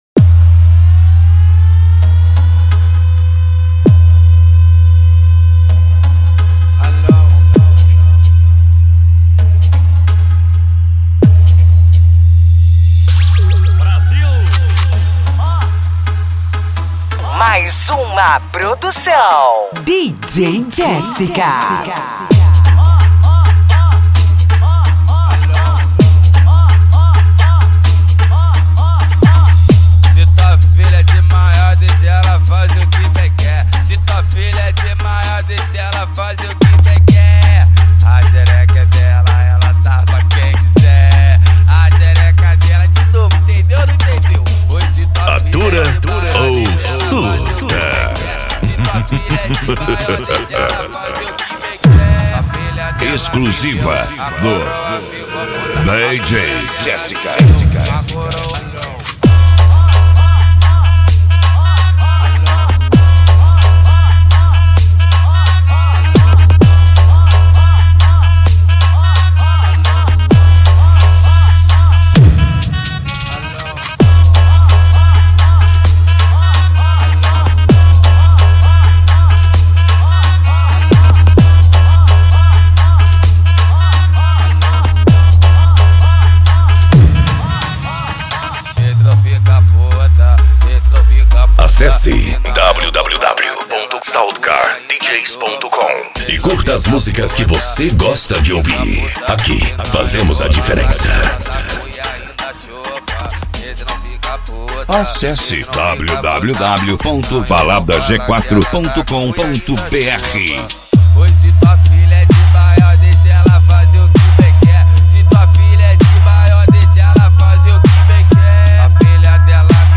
Bass
Racha De Som
Remix